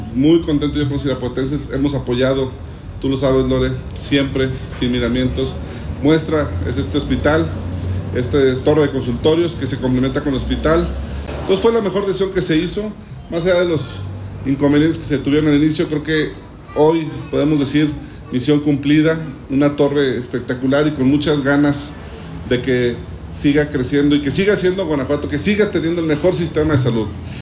Diego Sinhue Rodríguez Vallejo, gobernador de Guanajuato